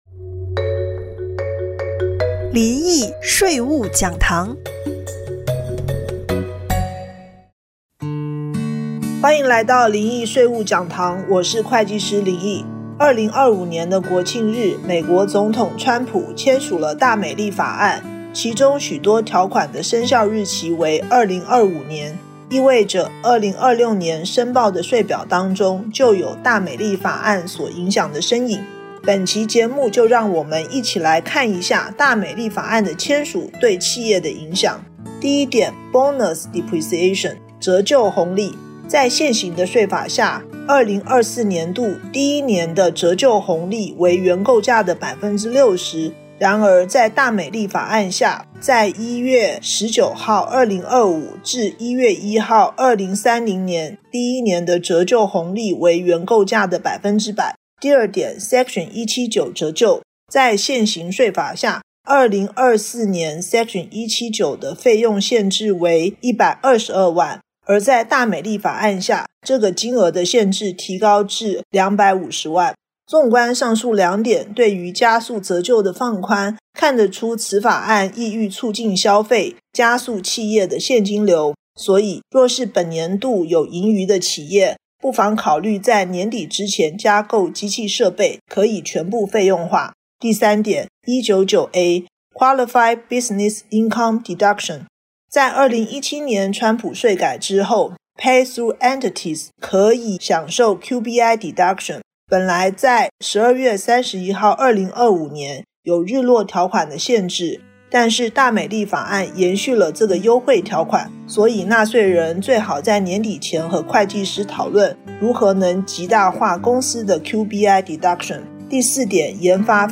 電台訪談